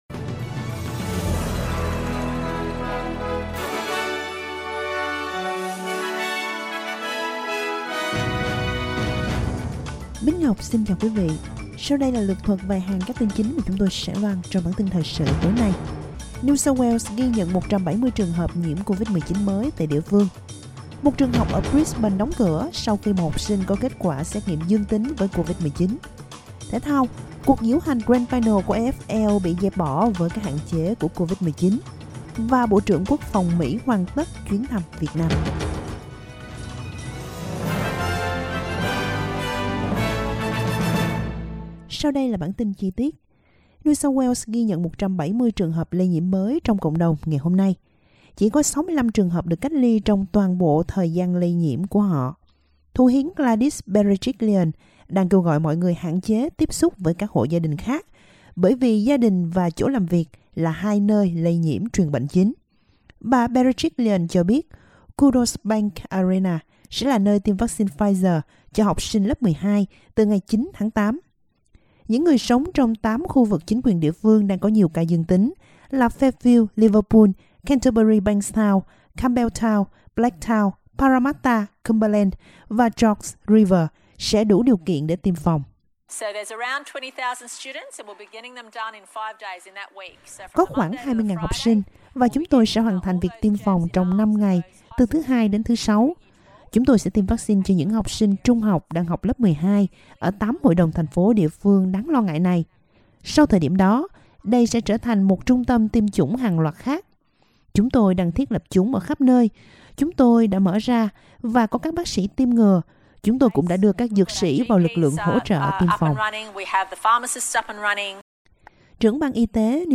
Các tin chính trong bản tin thời sự.